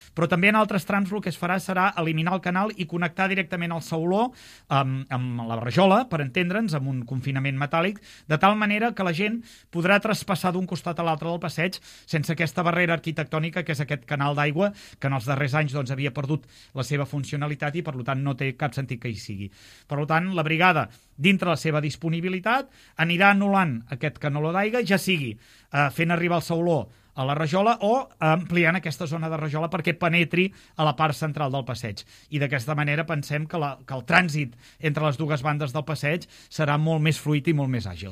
L’alcalde de Palamós, Lluís Puig, explica que la supressió de la canal d’aigües plujanes  al llarg de tot el passeig suposarà una millora important per als ciutadans. En declaracions a Ràdio Palamós, Puig destaca que a hores d’ara el canaló suposa un obstacle important per travessar aquest espai tan concorregut, i més tenint en compte que ja fa uns anys que va perdre la seva principal funció.